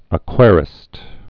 (ə-kwârĭst)